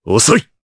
Riheet-Vox_Skill2_jp.wav